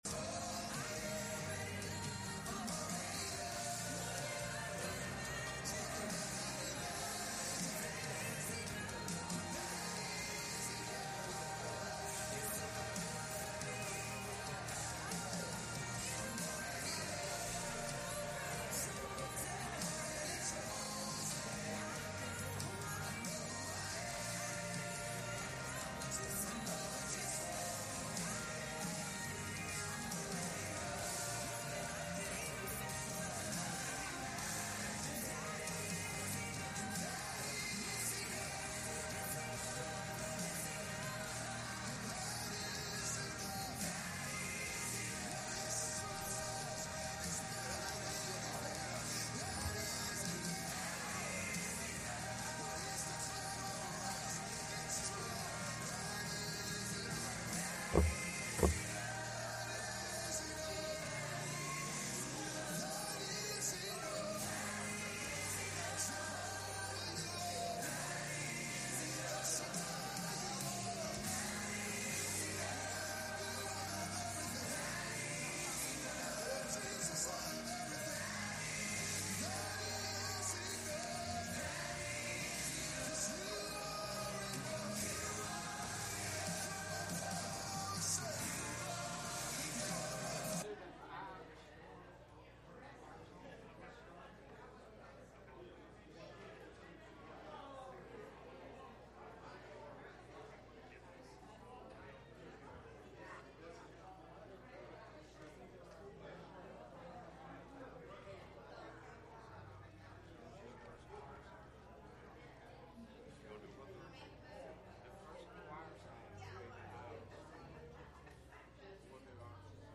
Acts 9:23-25 Service Type: Sunday Morning « In God We Trust The Three Conditions Of Man